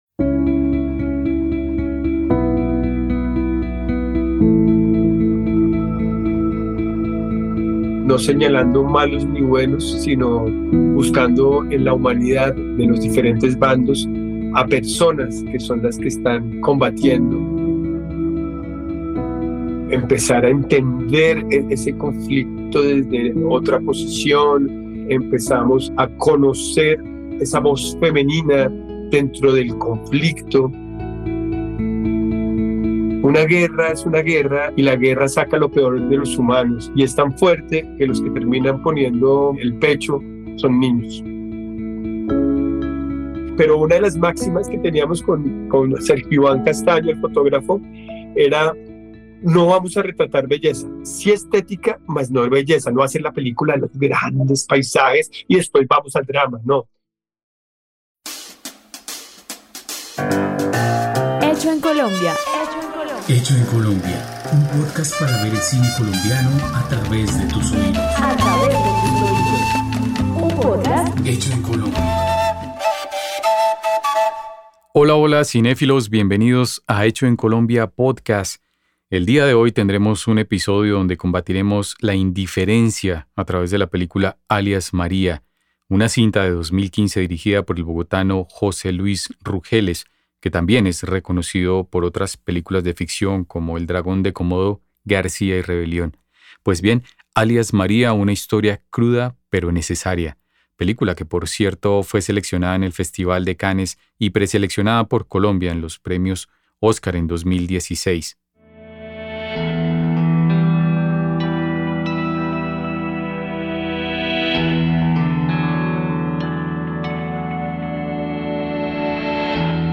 Fragmentos Audiovisuales: Alias María.
Estudio de grabación: Radiola Music Estudio.